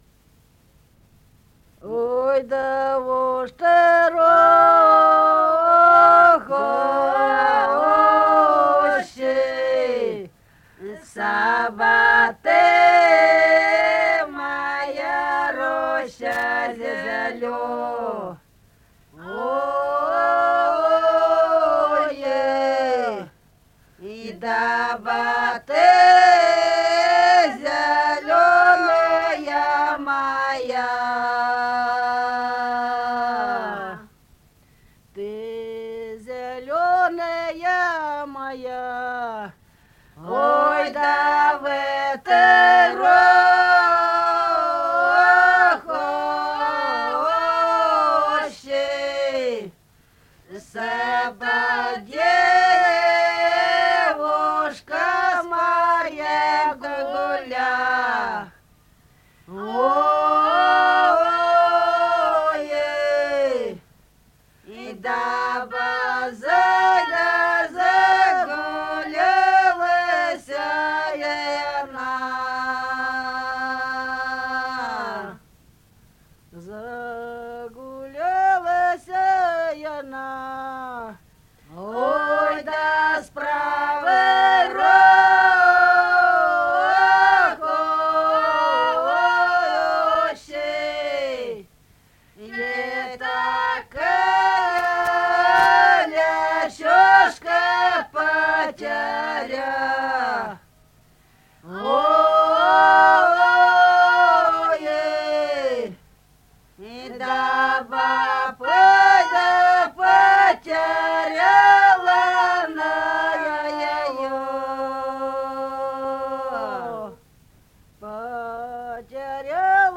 Песни Убинско-Ульбинской долины Ой, да уж ты, рощица (застольная